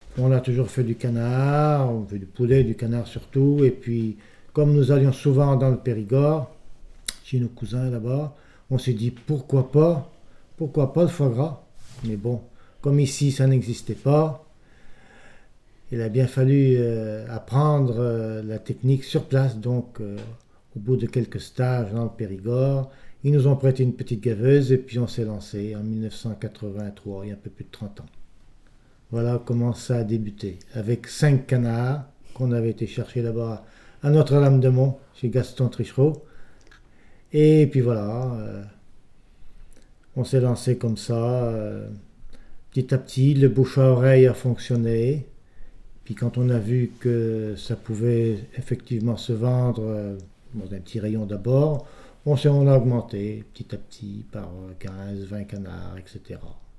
Il provient de Saint-Gervais.
Témoignage ( mémoire, activité,... )